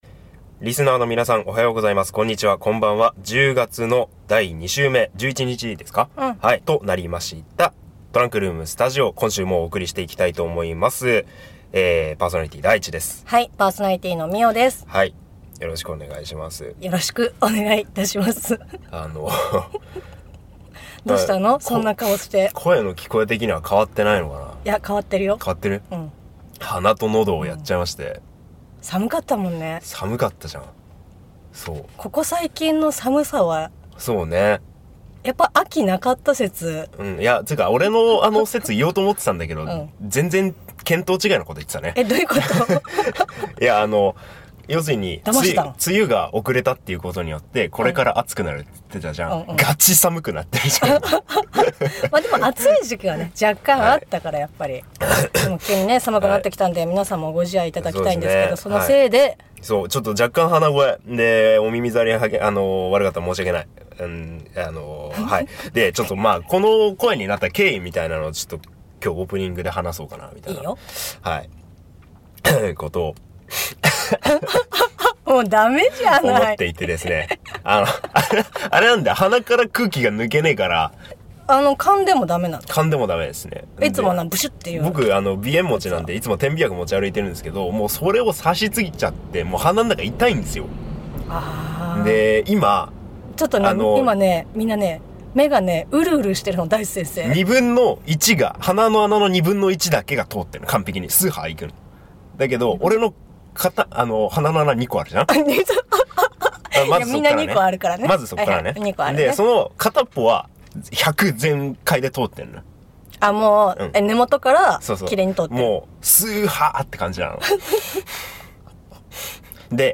第37回【トランクルーム・スタジオ】 今月のメッセージテーマ 『好きなスポーツ教えてください』にそって パーソナリティー２人のスポーツ遍歴についてお話しています！